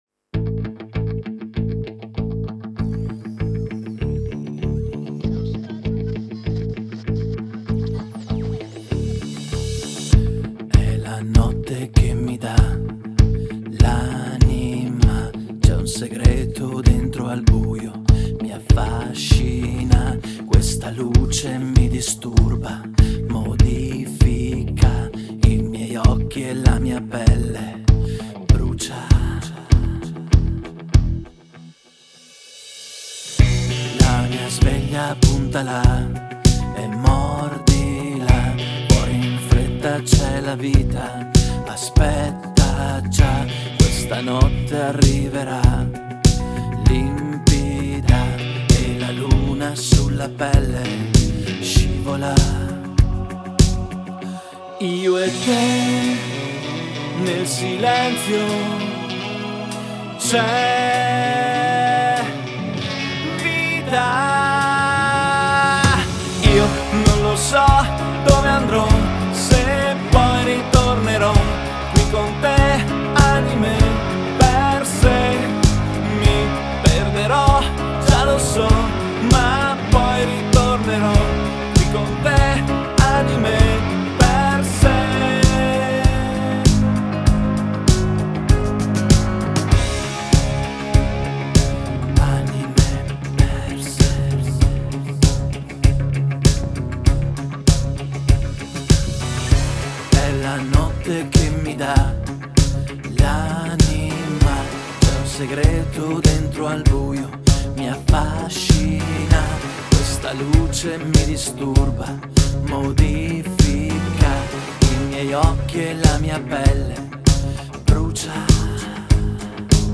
Luogo esecuzioneSANT'ARCANGELO DI ROMAGNA
GenerePop